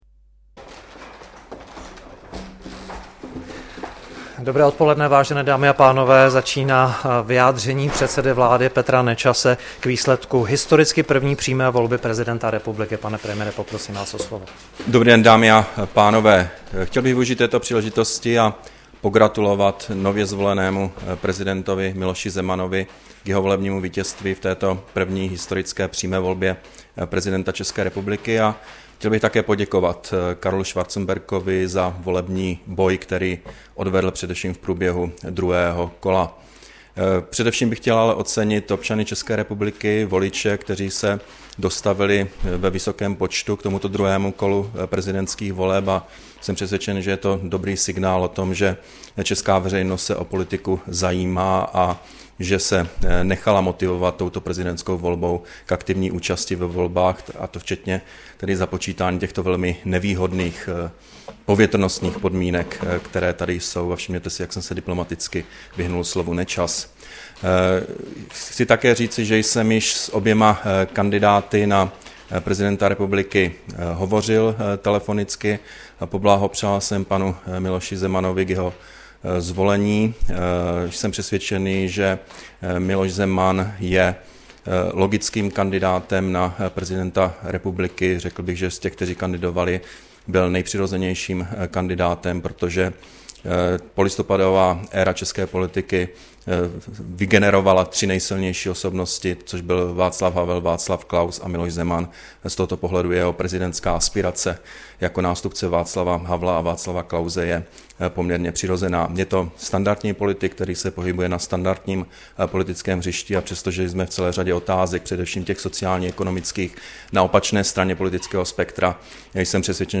Vyjádření premiéra k výsledku prezidentské volby, 26. ledna 2013